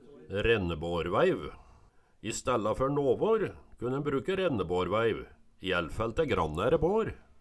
Høyr på uttala Ordklasse: Substantiv hokjønn Kategori: Reiskap og arbeidsutstyr Attende til søk